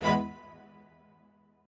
admin-leaf-alice-in-misanth…/strings34_1_005.ogg